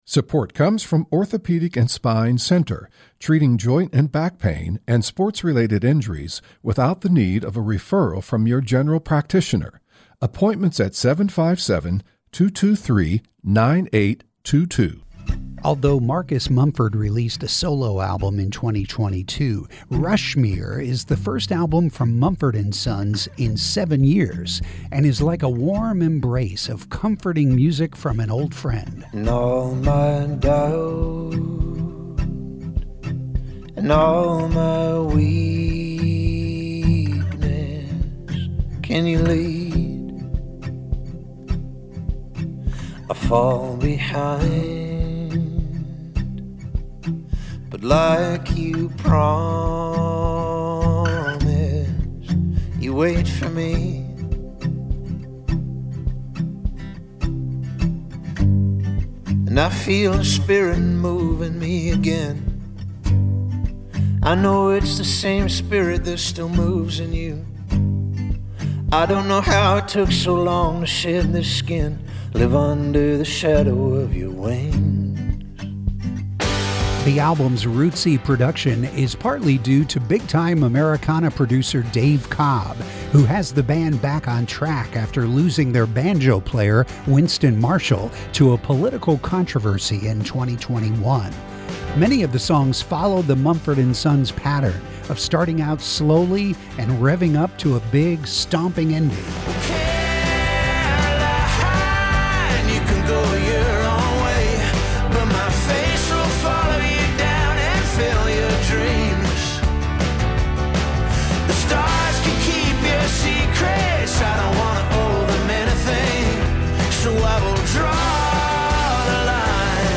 "Higher" is a masterful blend of country, soul, and blues that showcases his remarkable vocal range.